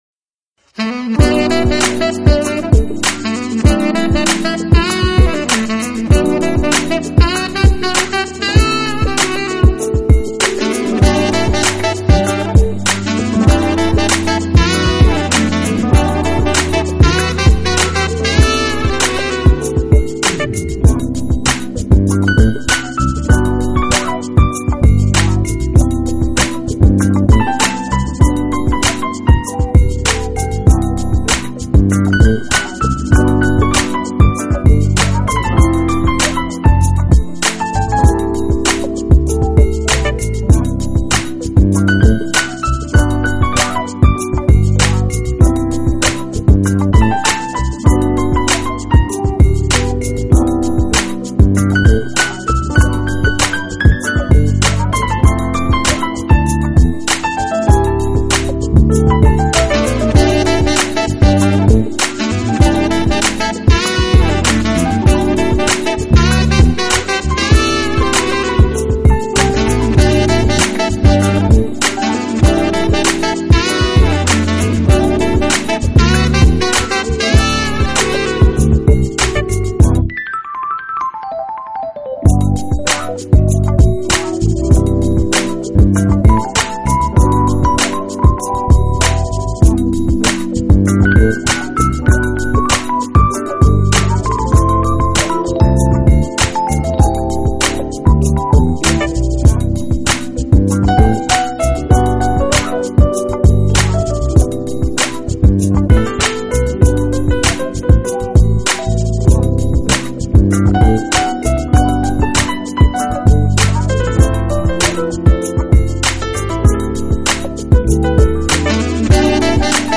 Genre: Jazz / R&B Style: Smooth Jazz / Nu Jazz
with soulful and sexy Jazz undertones
amazing guest vocalist and incredibly talented musicians